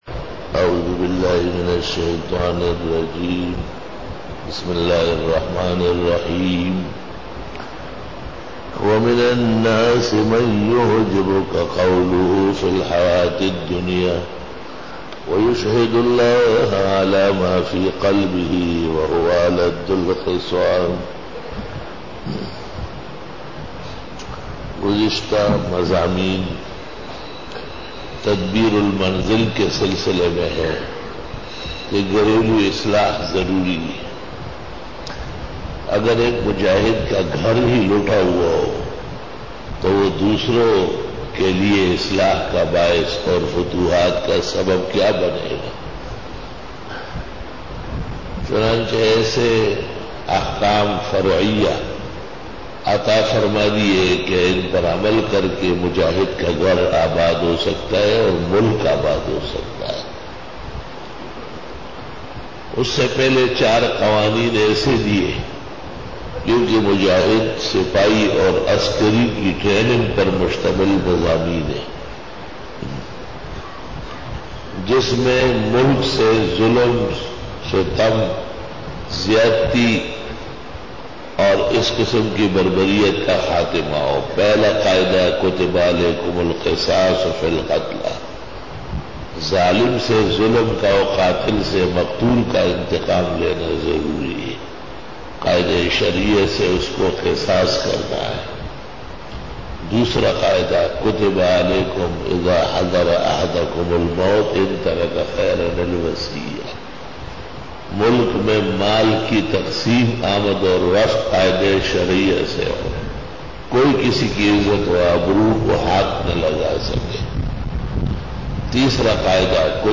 دورہ تفسیر | دن 14 | 14 جولائی 2013 Bayan